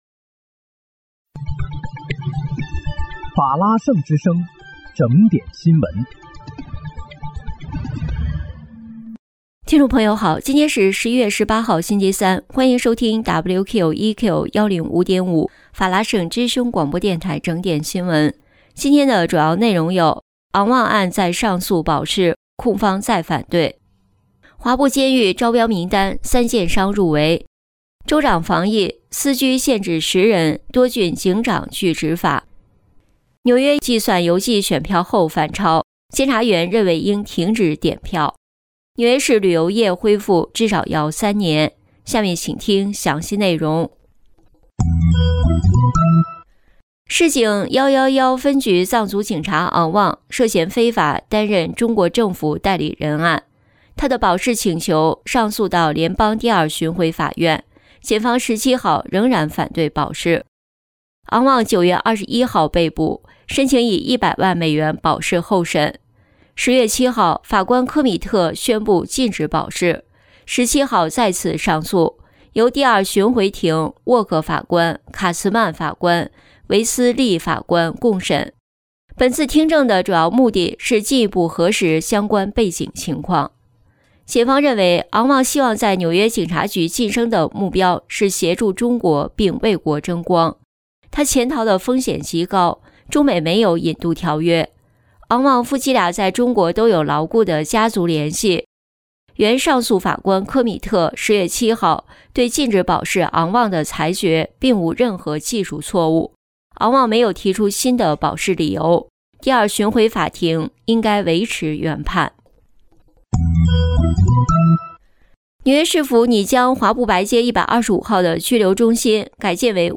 11月18日（星期三）纽约整点新闻